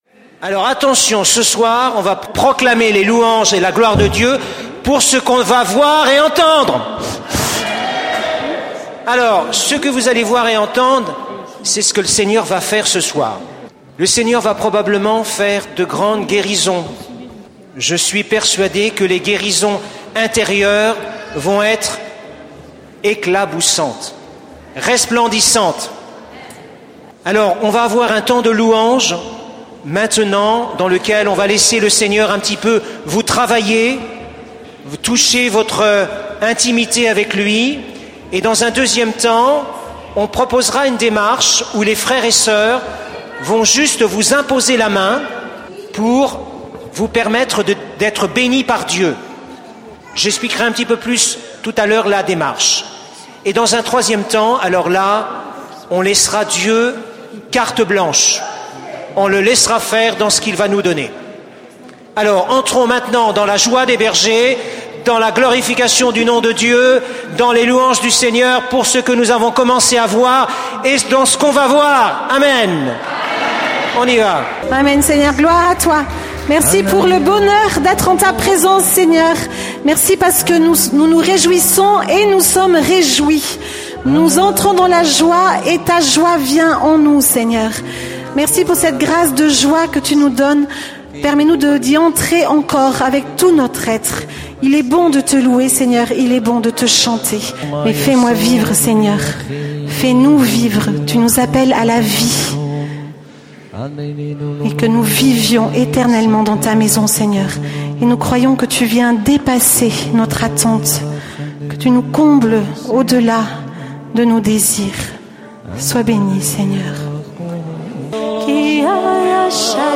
Enregistr� � la session des B�atitudes Lisieux 2014 Intervenant(s